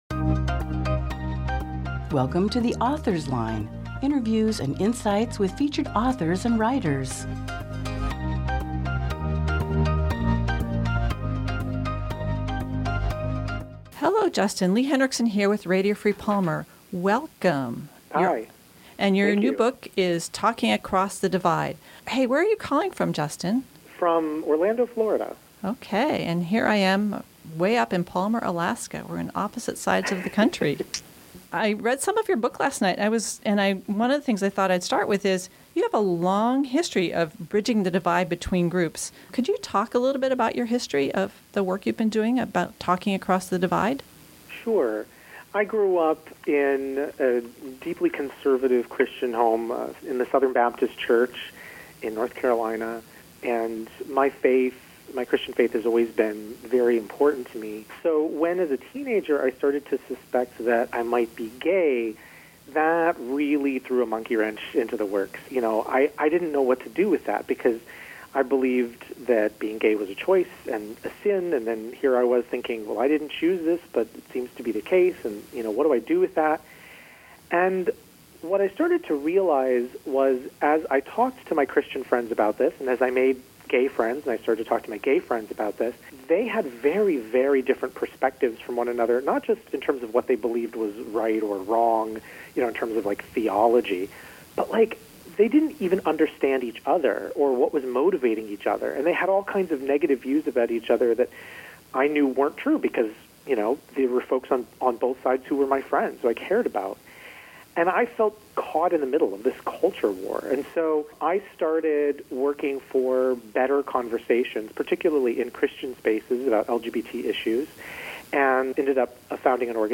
Aug 17, 2018 | Author Interviews